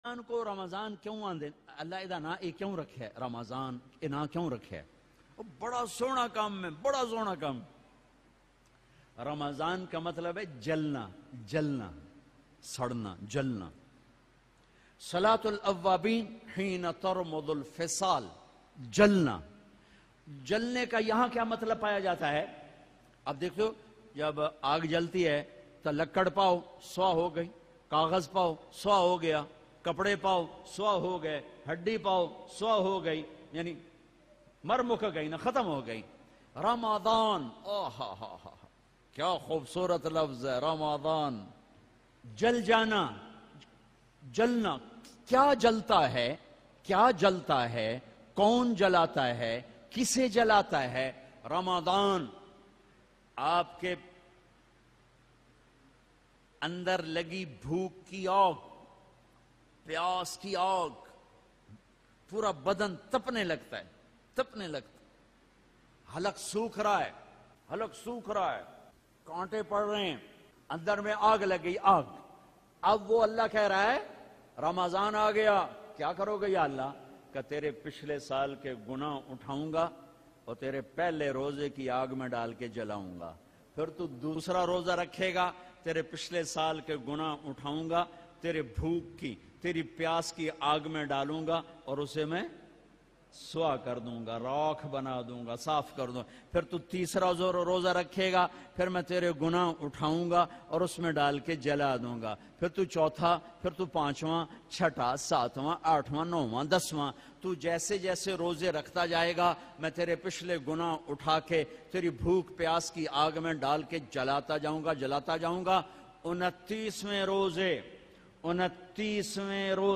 Ramzan Ki Fazilat Maulana Tariq Jameel Lattest Bayan